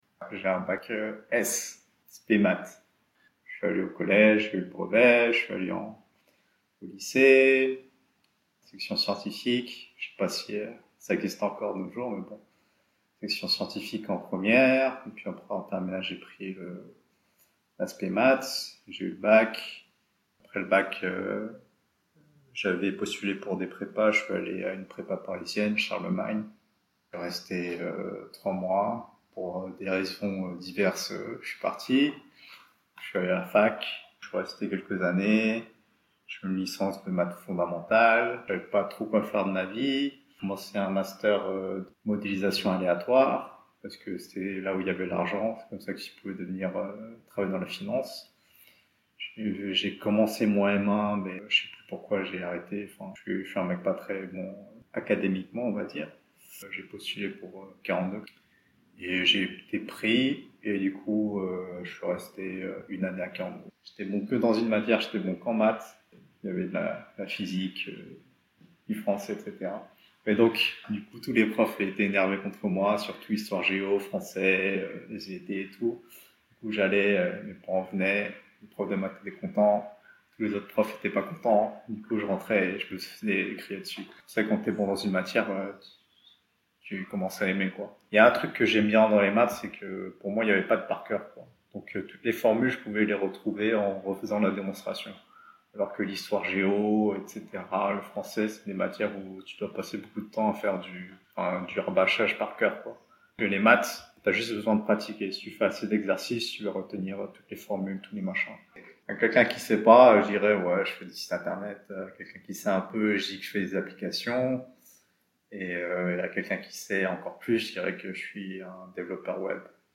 [AUDIO DE L’INTERVIEW ]